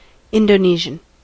Ääntäminen
Ääntäminen US Tuntematon aksentti: IPA : /indəˈniʒən/ Haettu sana löytyi näillä lähdekielillä: englanti Käännös Ääninäyte Adjektiivit 1. indonesisch Substantiivit 2.